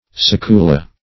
Search Result for " succula" : The Collaborative International Dictionary of English v.0.48: Succula \Suc"cu*la\, n. [L. sucula a winch, windlass, capstan.]